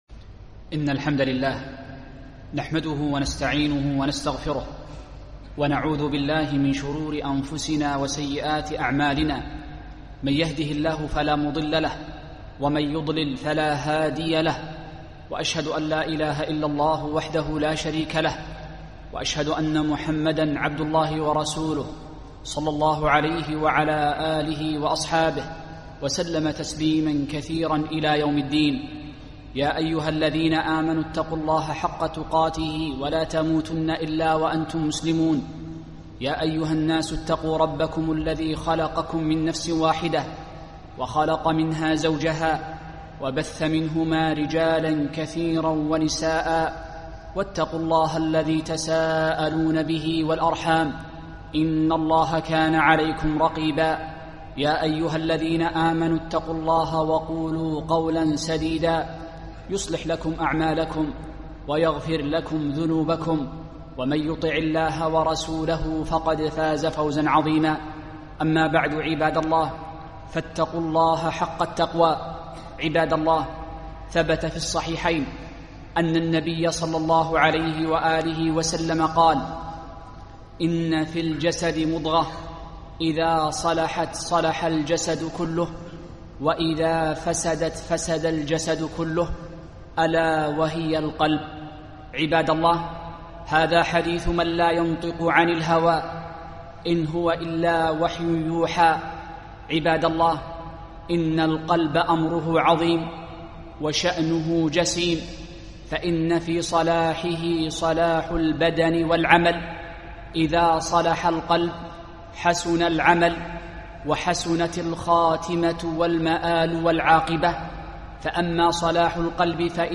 خطبة - القلب السليم